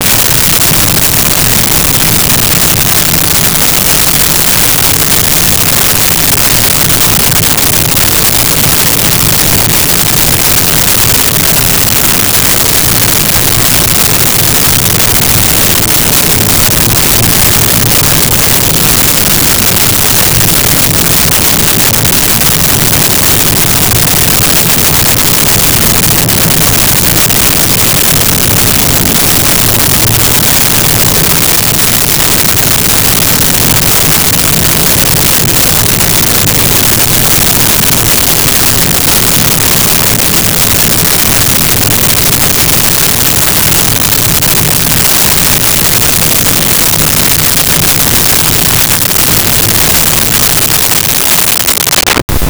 Lofi Field Drum Roll
LoFi Field Drum Roll.wav